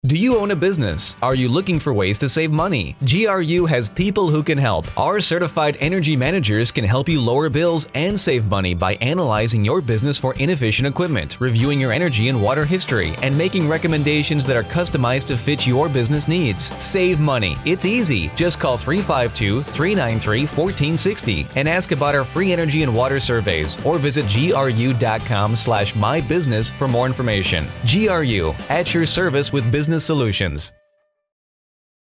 Length Radio Spot